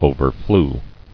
[o·ver·flew]